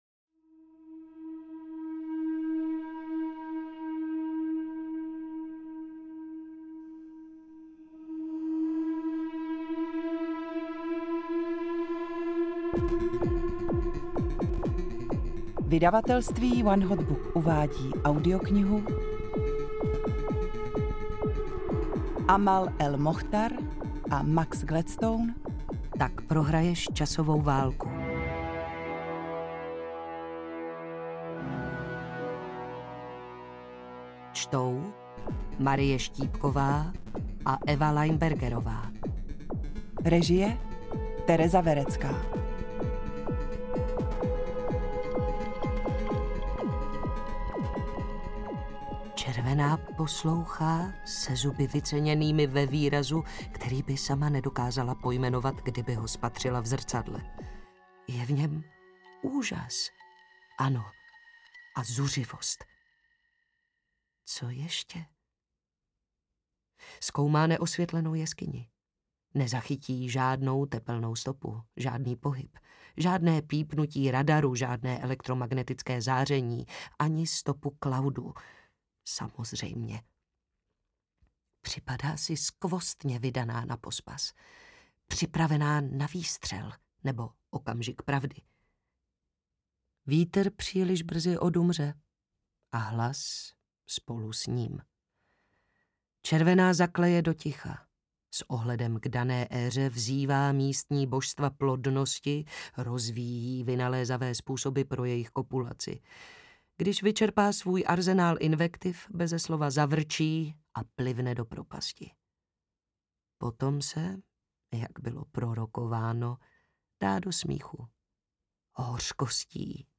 Tak prohraješ časovou válku audiokniha
Ukázka z knihy
tak-prohrajes-casovou-valku-audiokniha